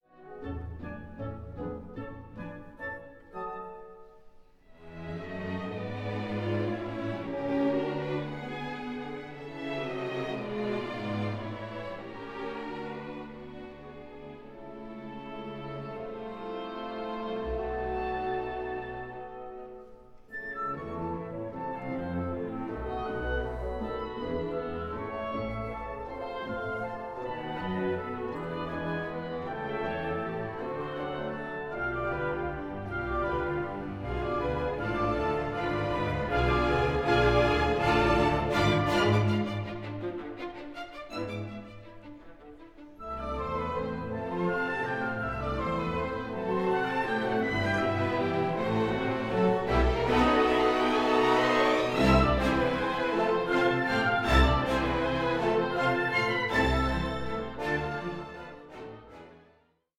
Symphony No. 2 in D Major, Op. 73